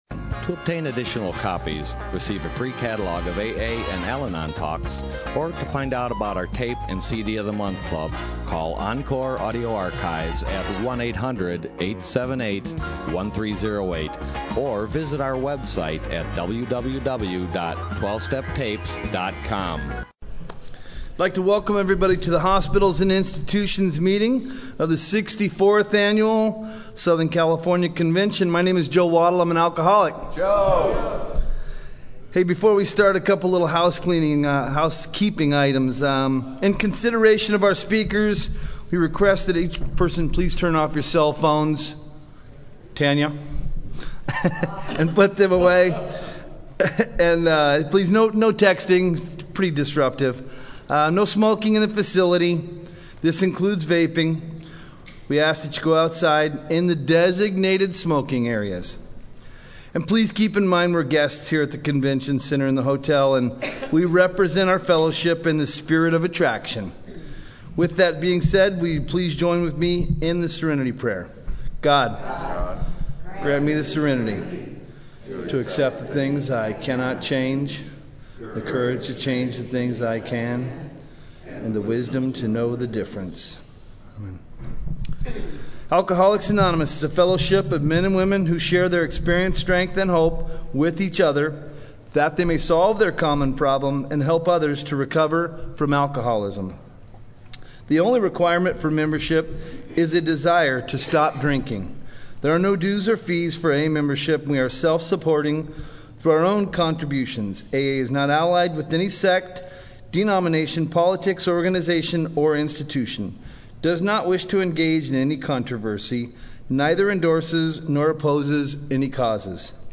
SoCAL AA Convention